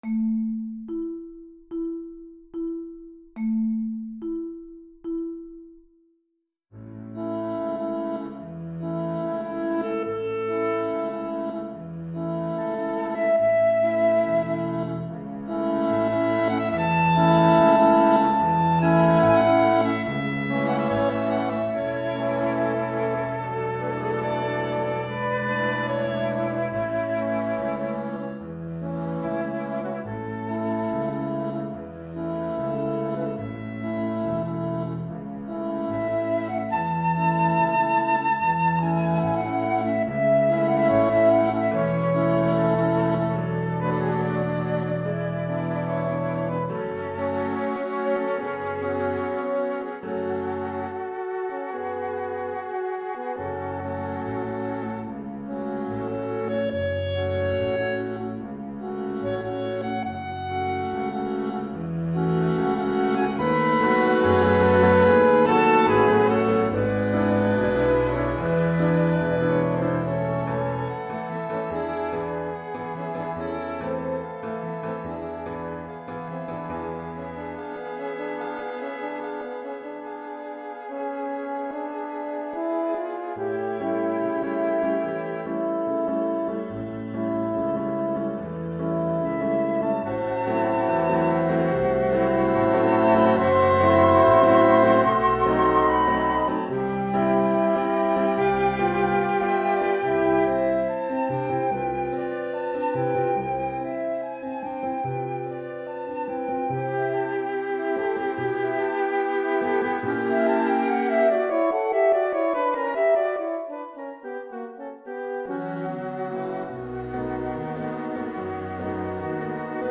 PlayAlong 3: Andante con moto